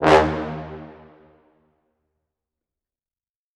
Dead Horn.wav